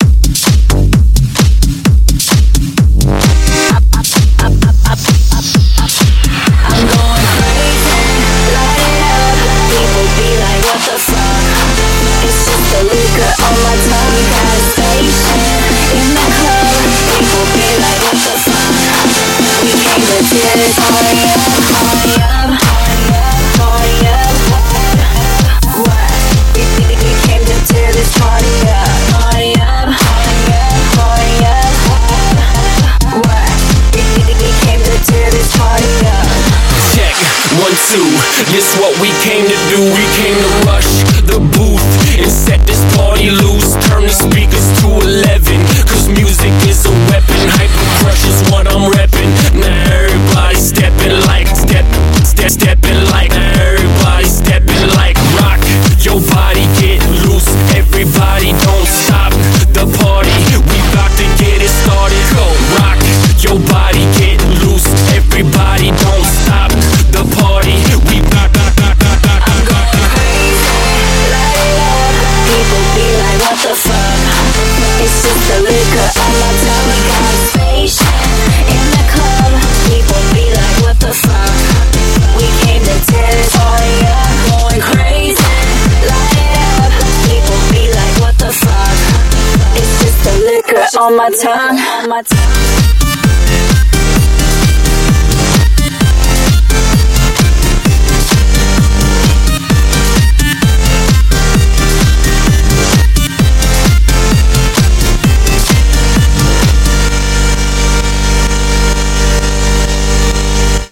BPM65-130
MP3 QualityMusic Cut